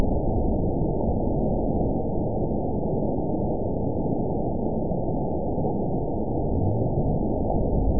event 917216 date 03/24/23 time 12:00:27 GMT (2 years, 1 month ago) score 8.27 location TSS-AB04 detected by nrw target species NRW annotations +NRW Spectrogram: Frequency (kHz) vs. Time (s) audio not available .wav